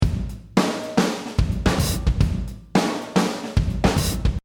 I have chosen 16th notes to suit the 16th note kick drum.
Now lets have a listen to the before and after, with the sequenced drums.
After elastic audio
drumsQ.mp3